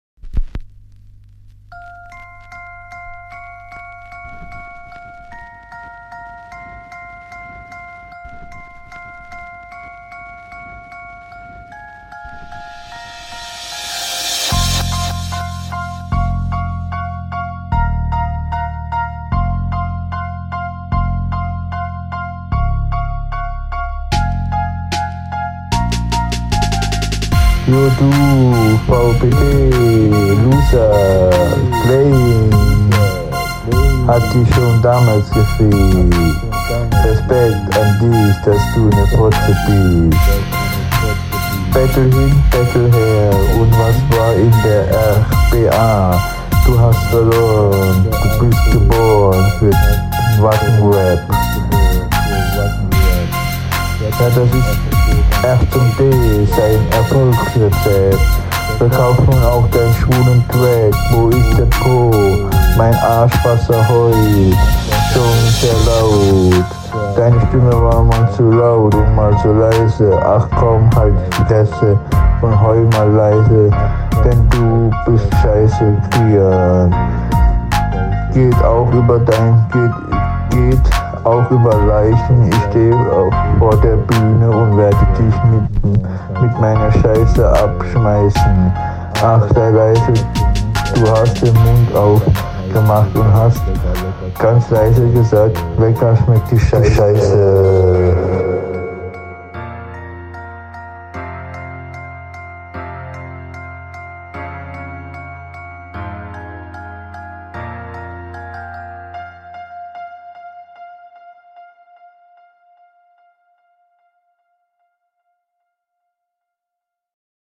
Man versteht dich wieder null.